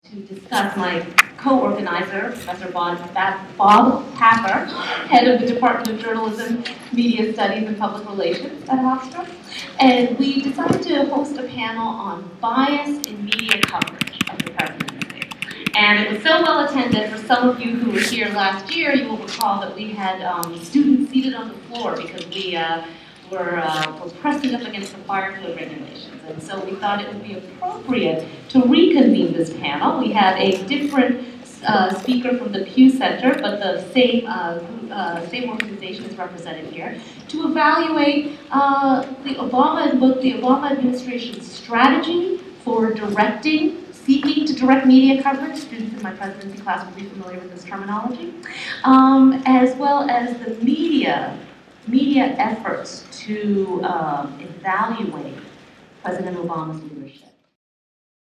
Location: Student Center Theater during a debate on the media’s coverage of Obama
Sounds heard: Talking, keyboard and computer sounds
Panel-debate.mp3